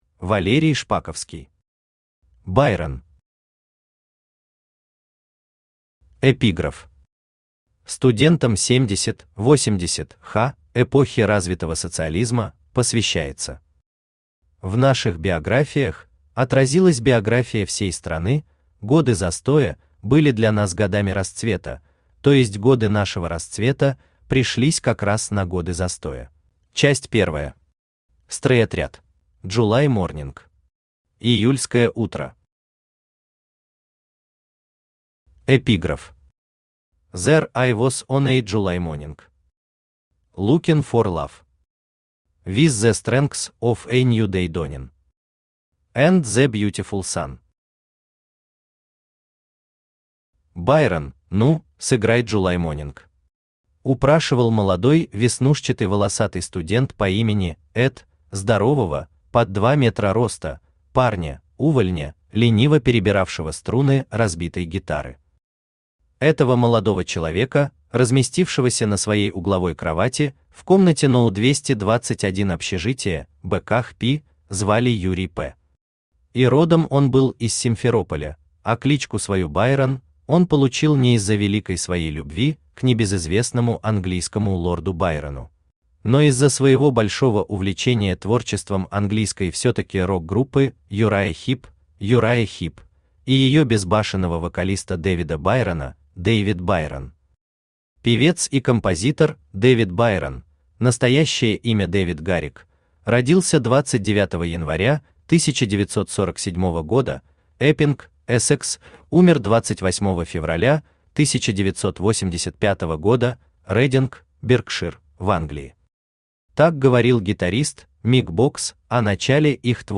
Aудиокнига Байрон Автор Валерий Николаевич Шпаковский Читает аудиокнигу Авточтец ЛитРес.